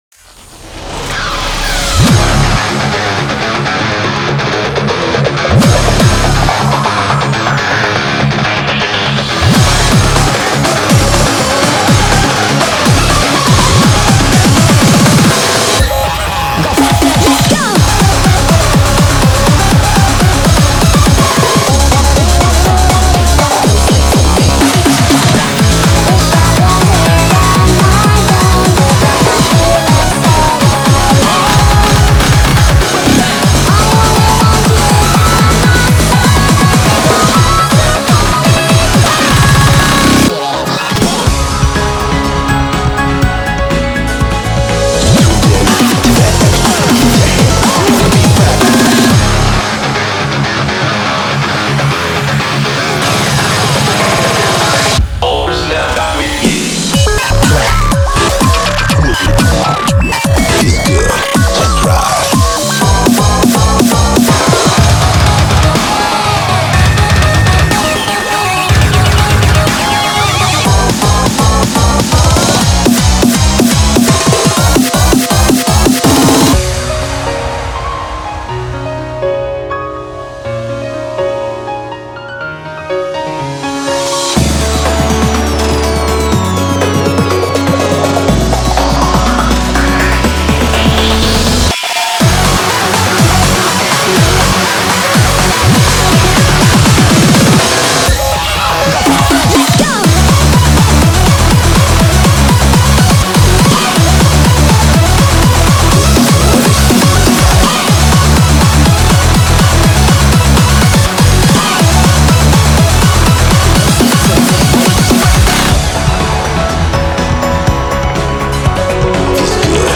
BPM61-245
Audio QualityPerfect (High Quality)
Comments[SPEEDCORE]
Song type: Otoge